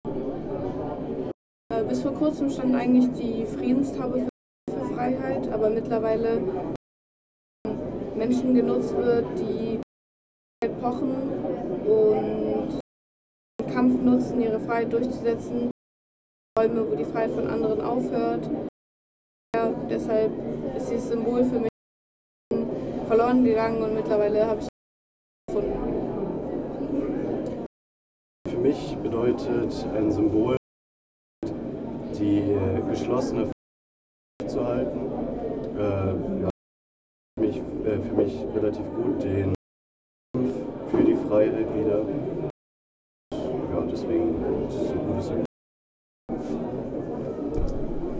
Standort der Erzählbox:
Stendal 89/90 @ Stendal